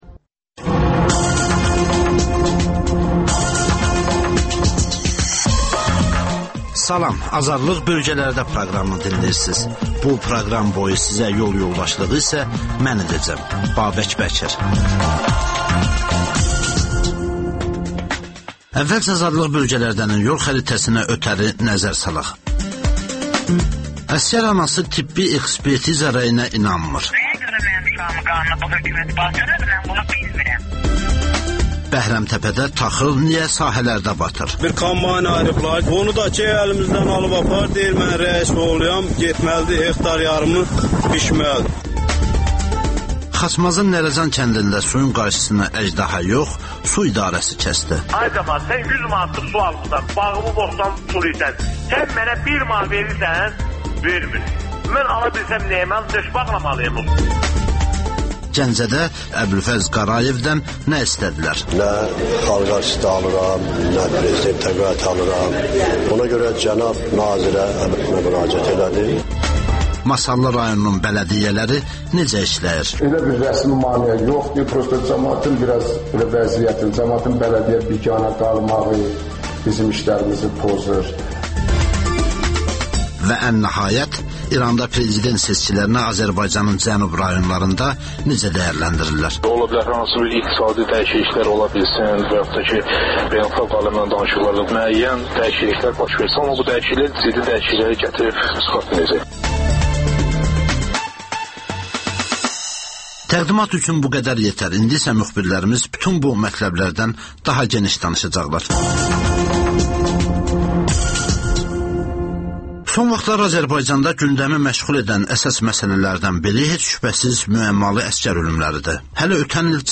Rayonlardan xüsusi reportajlar